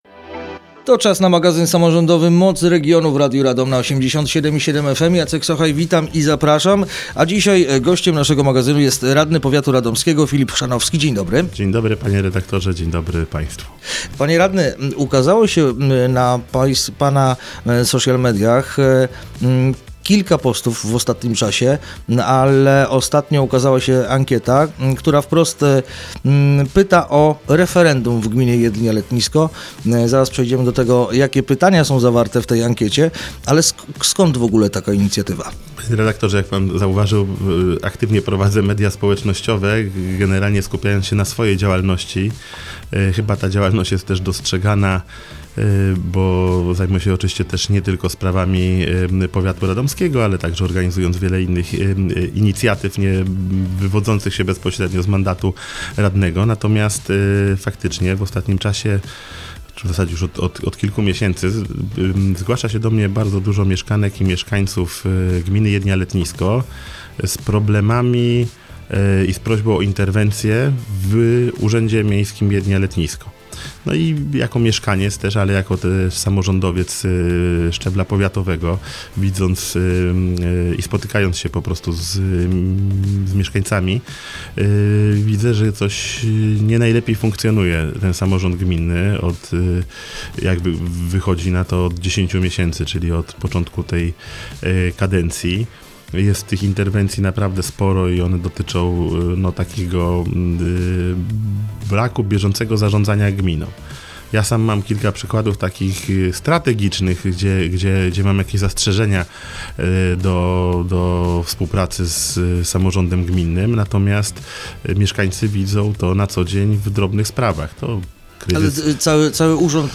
Radny Rady Powiatu Filip Chrzanowski